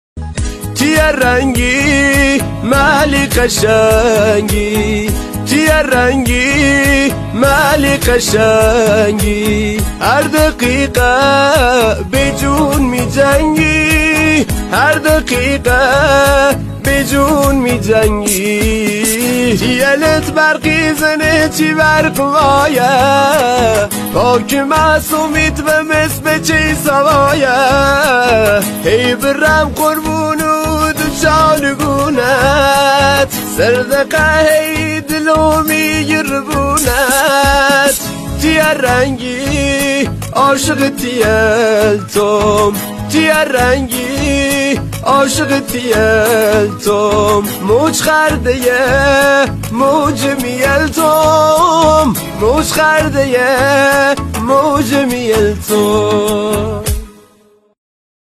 موزیک لری